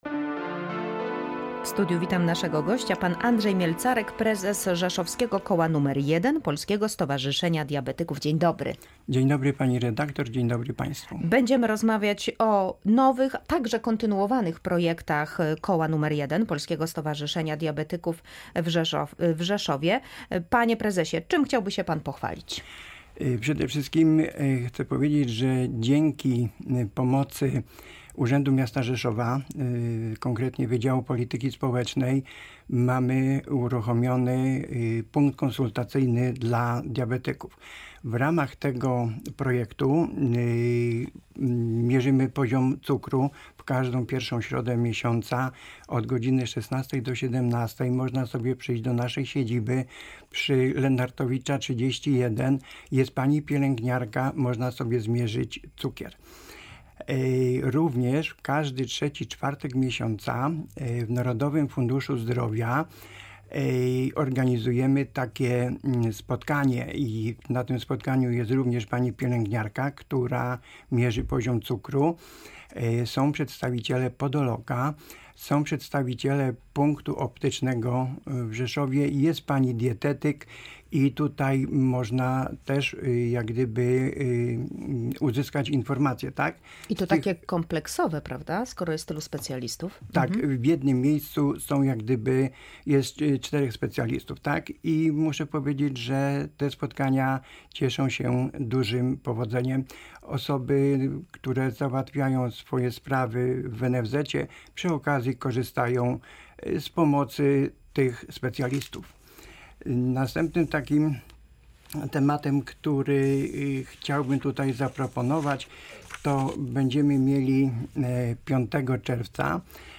W "Tu i teraz" rozmawialiśmy o najnowszych projektach Koła nr 1 w Rzeszowie Polskiego Stowarzyszenia Diabetyków.